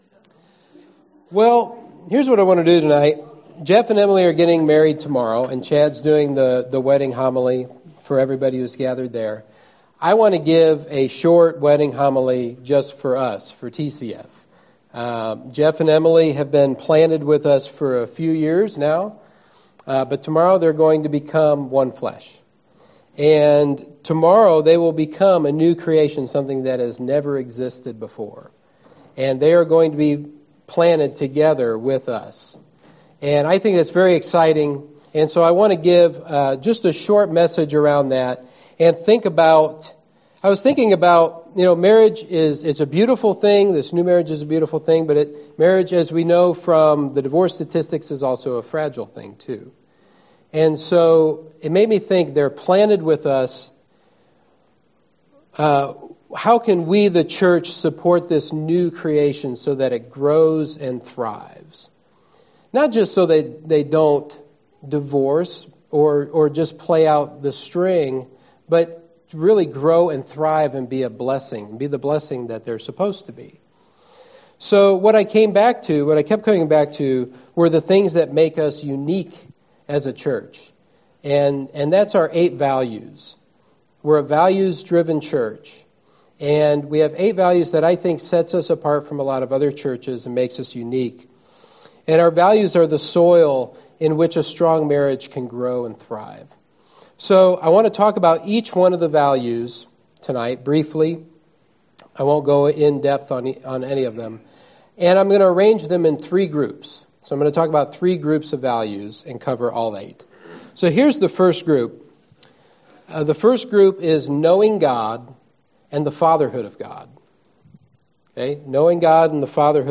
Sermon 4/29: Eight unique values will make marriage grow and thrive